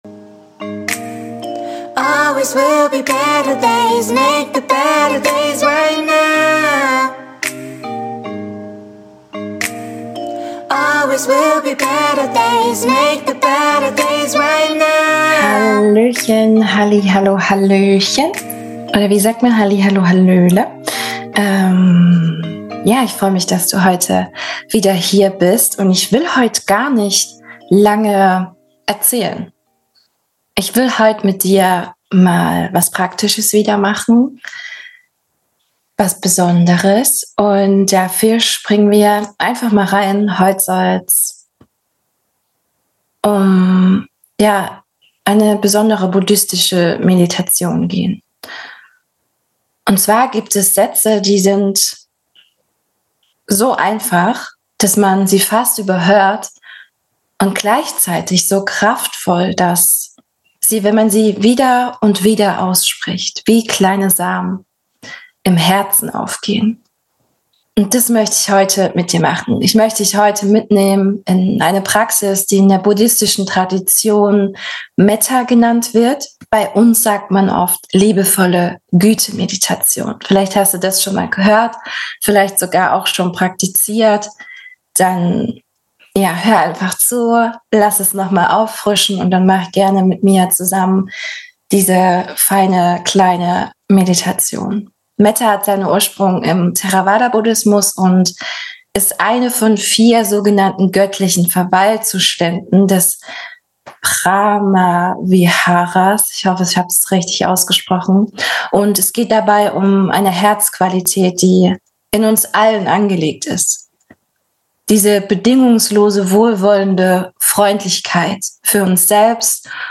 Beschreibung vor 7 Monaten Liebevolle Güte Meditation - Startet ca. ab Minute 12 Heute lade ich dich dazu ein, mit mir eine meiner liebsten Übungen zu machen - die Liebevolle Güte Meditation auch Metta-Meditation genannt.